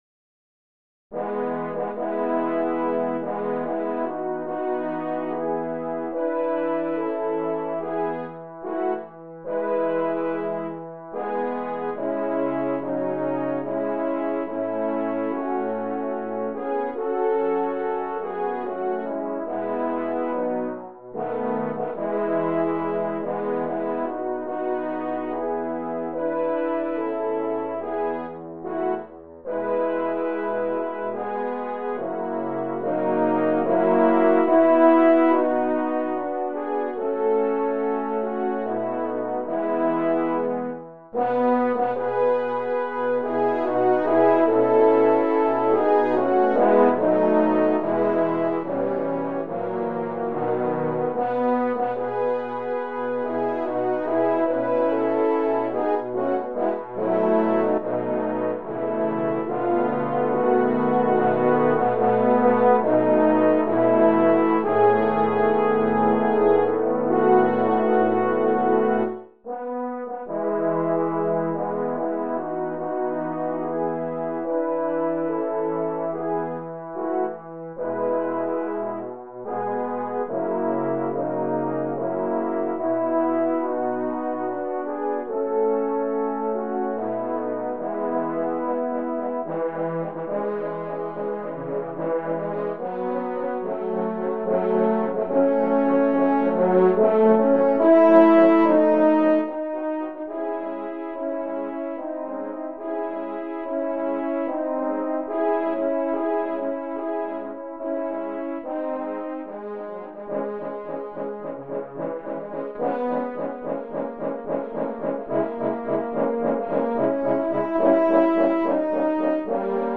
Voicing: Horn Sextet